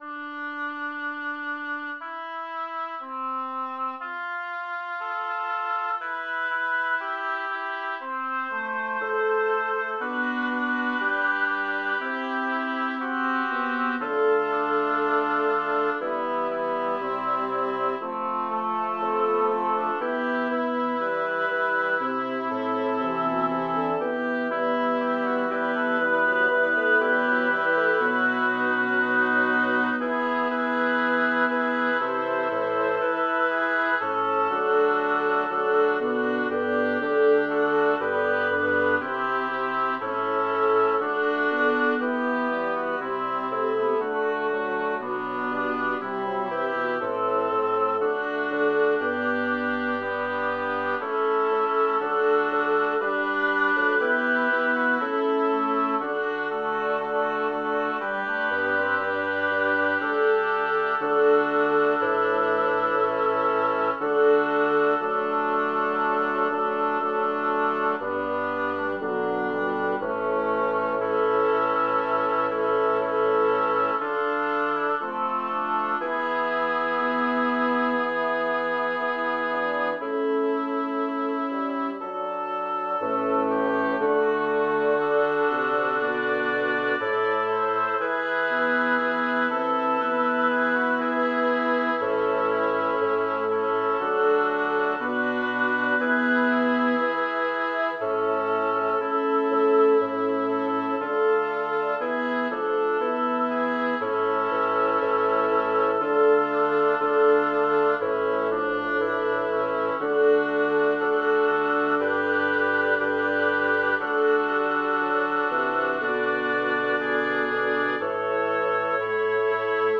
Title: Alla dolce ombra Composer: Hippolito Sabino Lyricist: Girolamo Malipiero Number of voices: 6vv Voicing: SSATTB Genre: Sacred, Madrigal
Language: Italian Instruments: A cappella